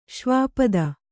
♪ śvāpada